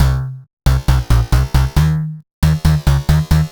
Index of /musicradar/future-rave-samples/136bpm